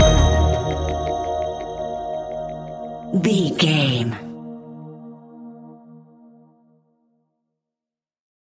Ionian/Major
D♯
electronic
techno
trance
synths
synthwave